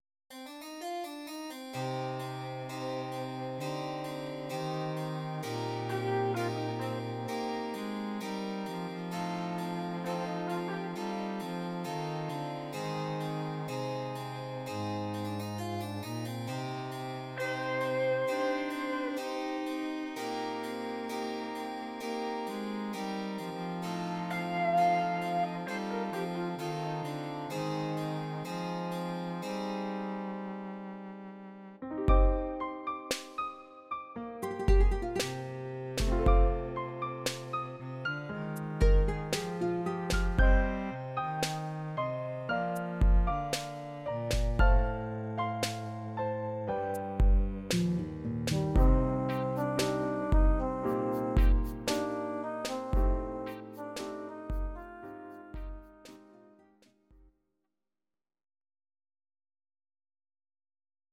These are MP3 versions of our MIDI file catalogue.
Please note: no vocals and no karaoke included.
Your-Mix: Pop (21635)